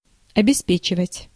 Ääntäminen
IPA: /puʁ.vwaʁ/